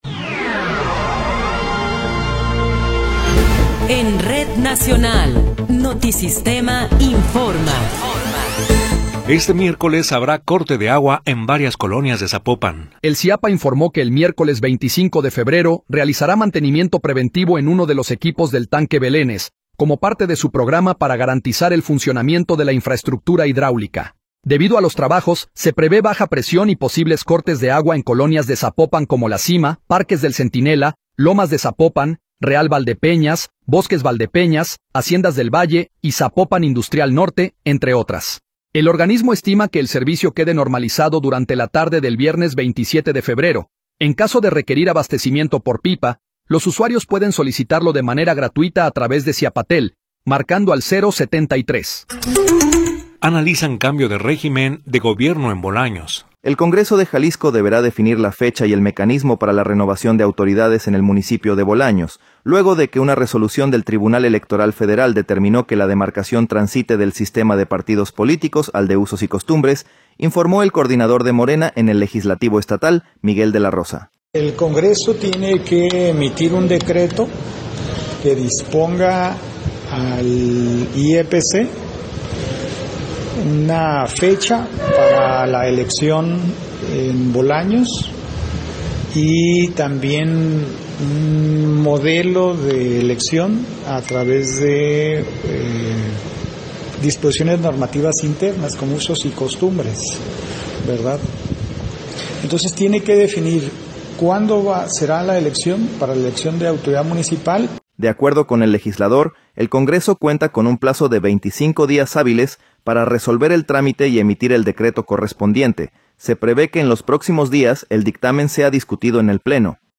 Noticiero 18 hrs. – 24 de Febrero de 2026
Resumen informativo Notisistema, la mejor y más completa información cada hora en la hora.